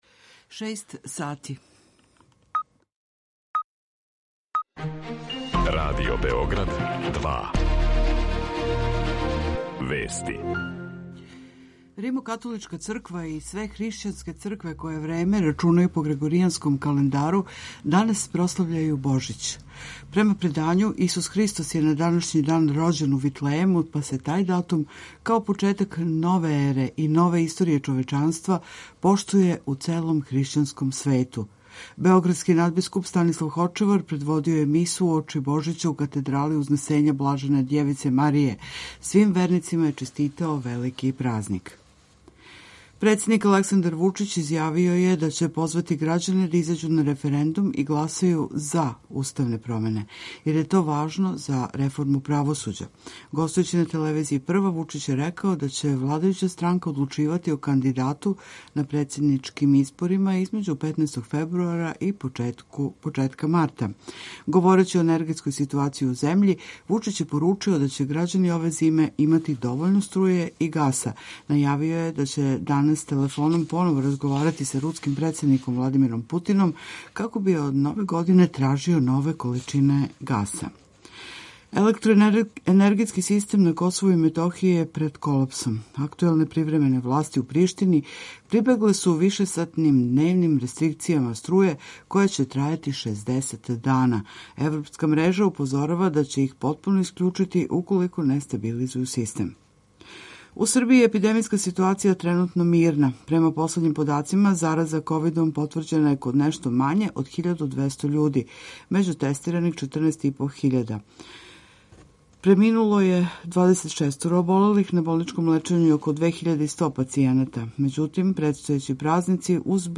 Сведочења и беседе Његове преузвишености београдског надбискупа и митрополита Станислава Хочевара.